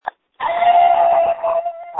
This is just a sample of the many screams recorded on January 7, 2021.
• When you call, we record you making sounds. Hopefully screaming.